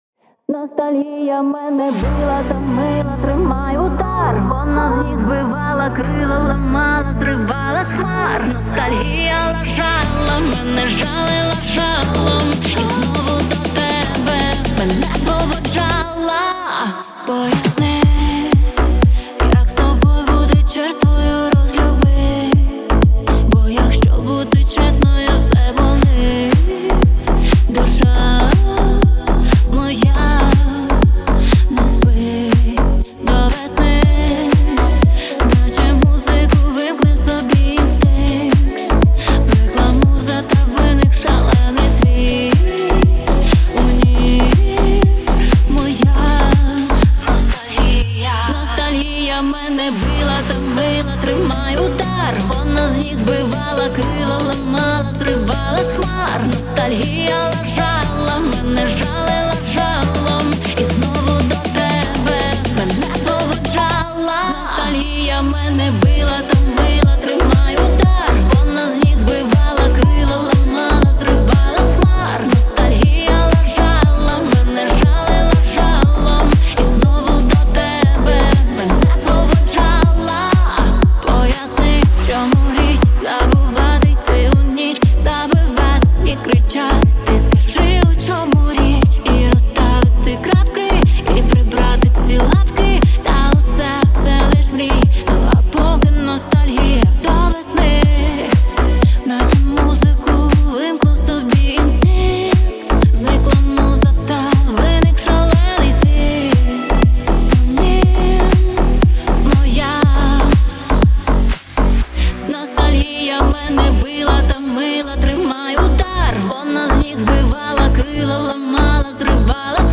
• Жанр: Pop, Dance